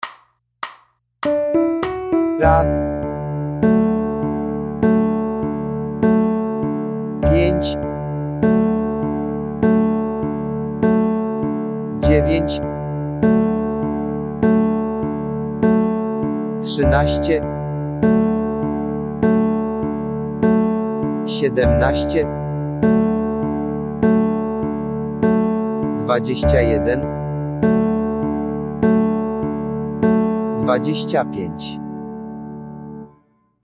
Załączamy podkład do ćwiczenia. Podkład "liczy" naszą długość dźwięku.
do_re_mi_re_do.mp3